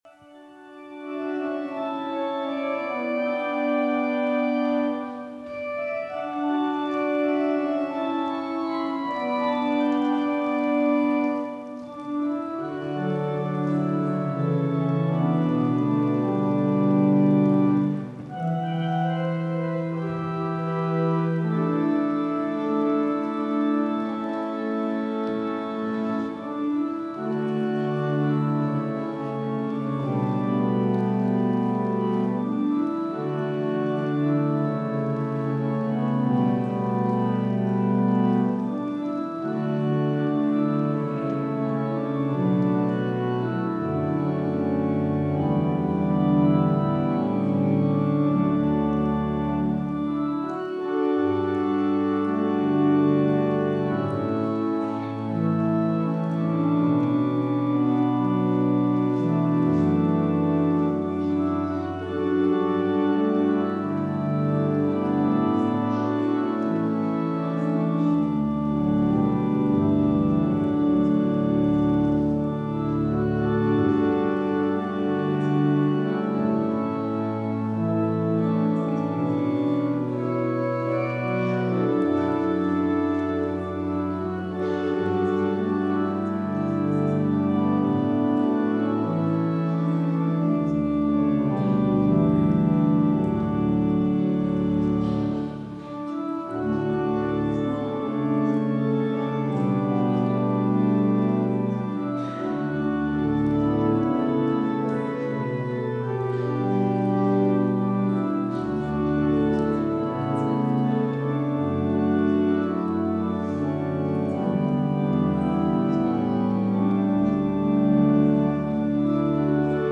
14. Orgelstück zum Ausgang
Audiomitschnitt unseres Gottesdienstes vom 19. Sonntag nach Trinitatis 2025.